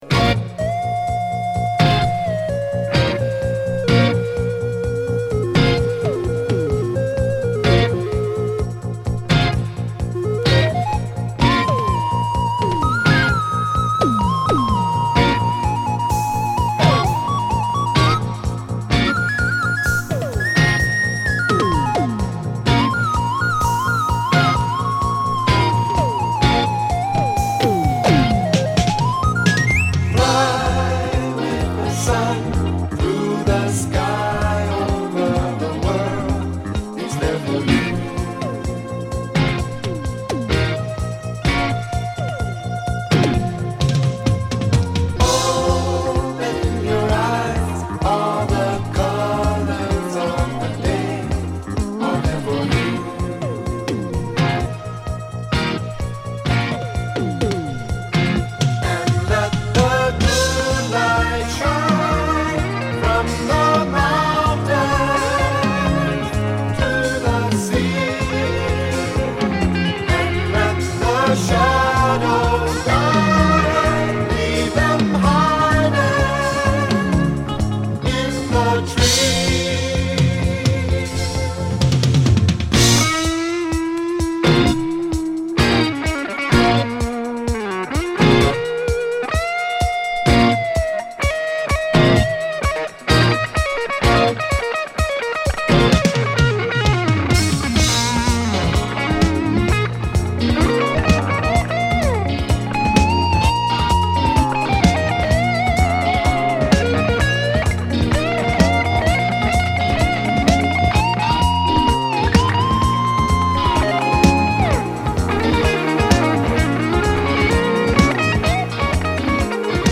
全体に爽快なサウンドが並ぶ1枚です！